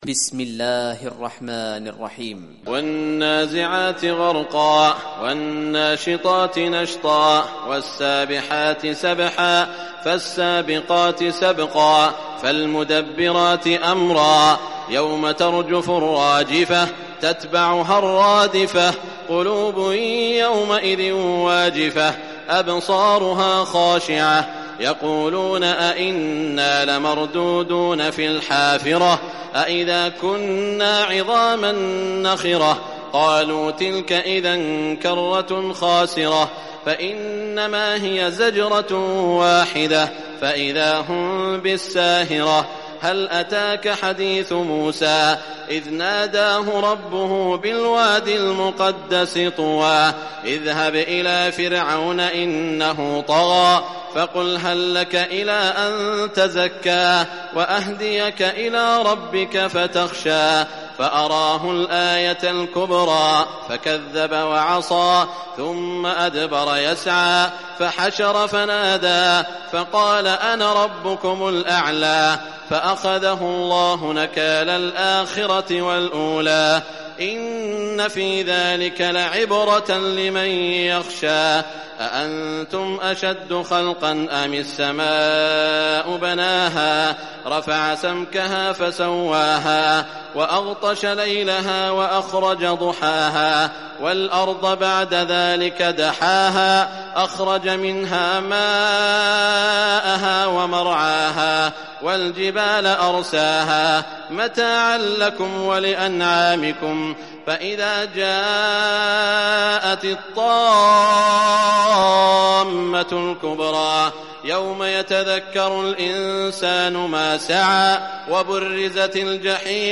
Surah Naziat Recitation by Sheikh Saud Shuraim
Surah Naziat, listen or play online mp3 tilawat / recitation in Arabic in the beautiful voice of Sheikh Saud al Shuraim.